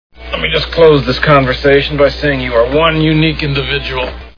Planes, Trains and Automobiles Movie Sound Bites
close_conversation.wav